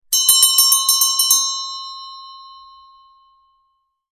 Category: SFX Ringtones